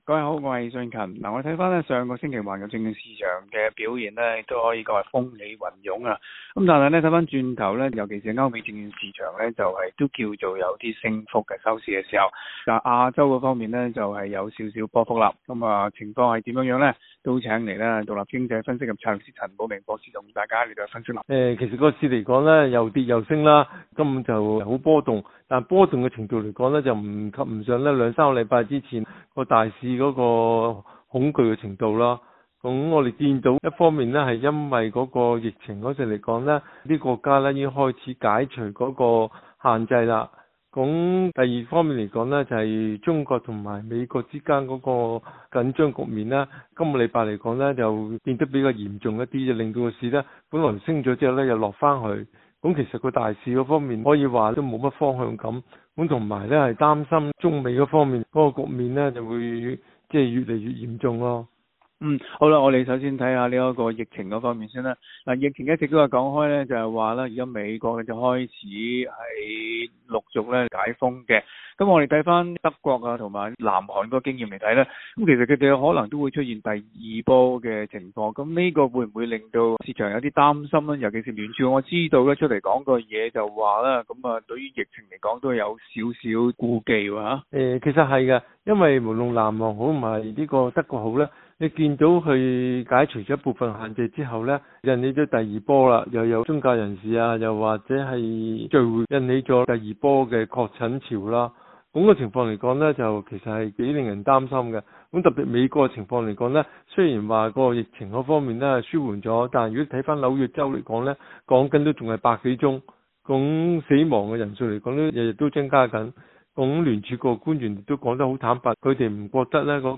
Source: Moment RF SBS廣東話節目 View Podcast Series Follow and Subscribe Apple Podcasts YouTube Spotify Download (19.35MB) Download the SBS Audio app Available on iOS and Android 上週市場在【希望】與【現實】的因素互為影響下，跌跌撞撞的走下去。